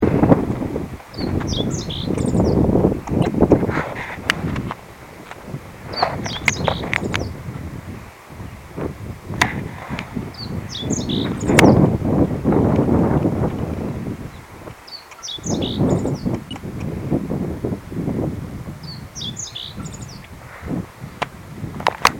meadow_bunting_1.MP3